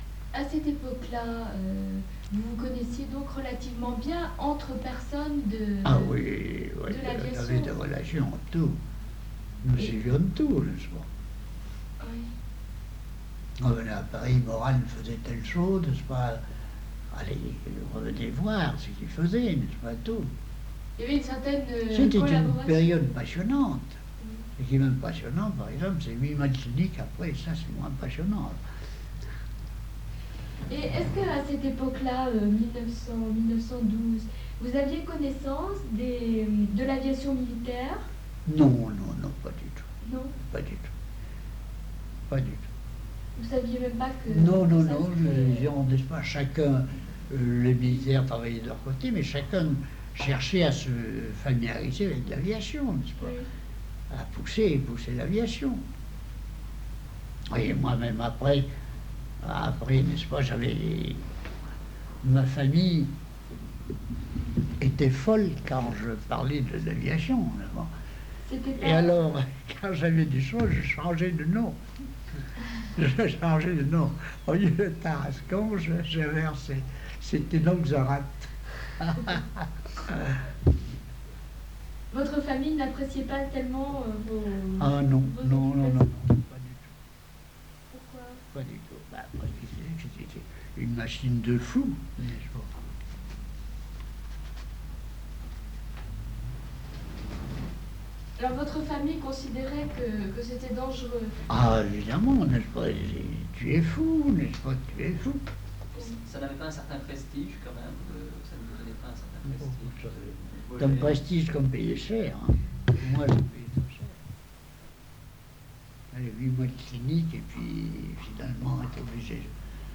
Entretien réalisé le 18 novembre 1975